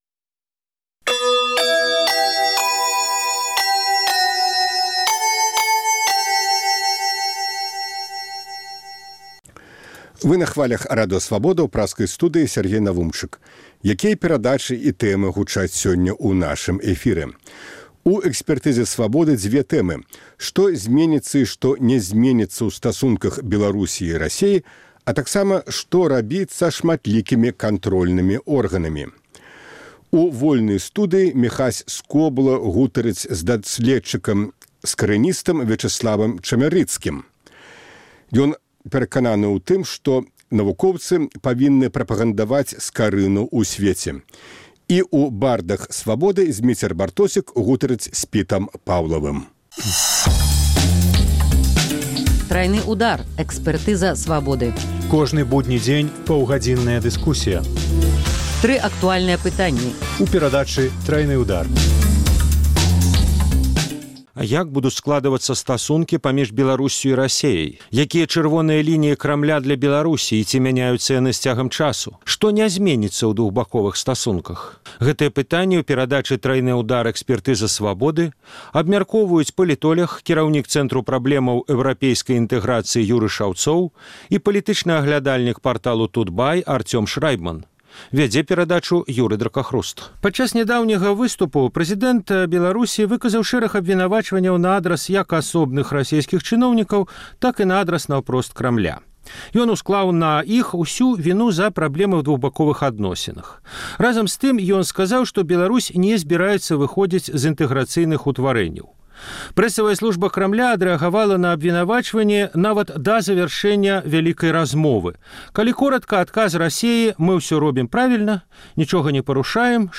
Пытаньне для трох аналітыкаў. Як будуць складвацца стасункі паміж Беларусьсю і Расеяй? Якія «чырвоныя лініі» Крамля для Беларусі і ці мяняюцца яны з цягам часу?